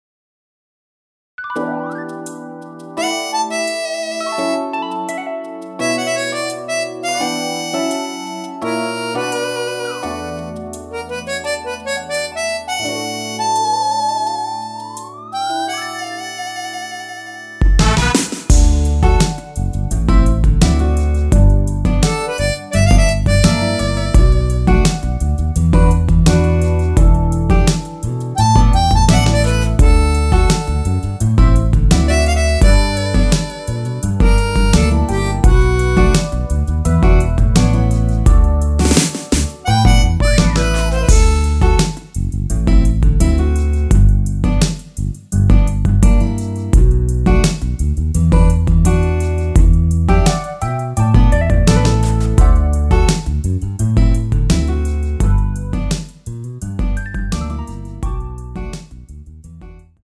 MR입니다.
앞부분30초, 뒷부분30초씩 편집해서 올려 드리고 있습니다.
중간에 음이 끈어지고 다시 나오는 이유는